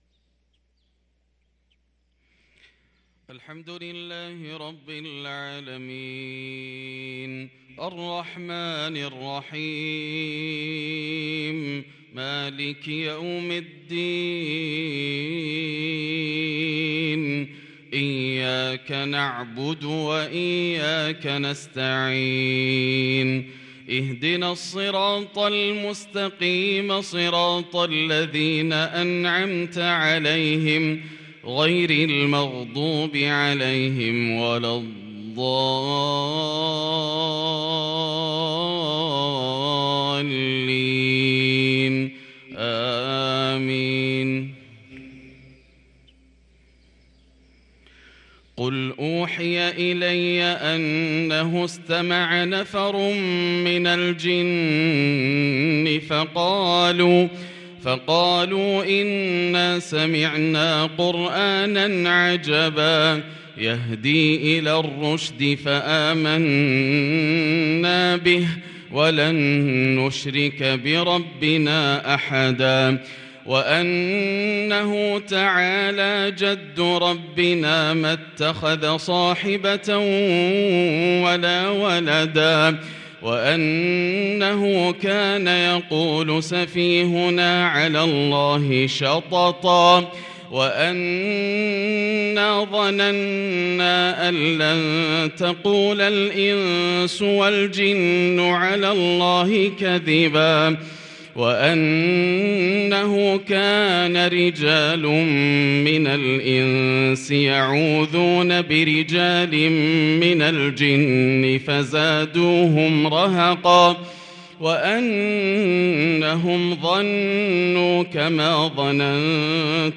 صلاة الفجر للقارئ ياسر الدوسري 26 شعبان 1443 هـ
تِلَاوَات الْحَرَمَيْن .